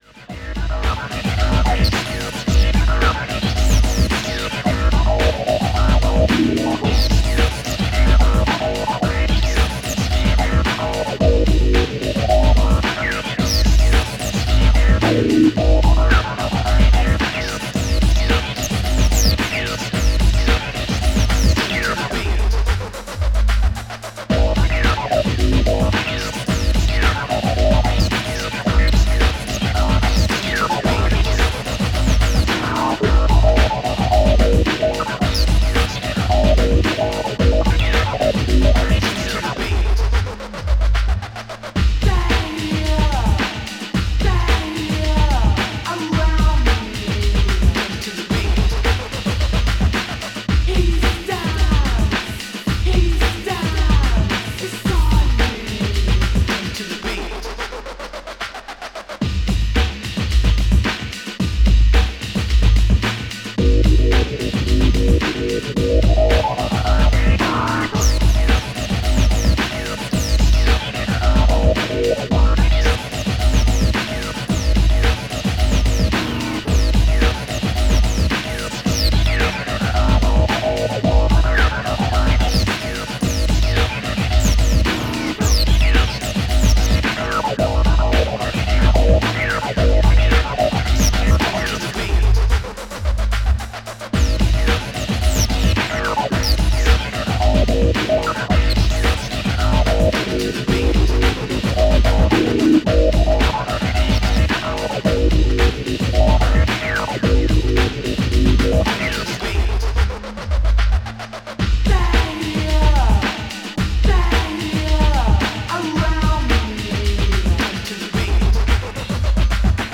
STYLE Breakbeat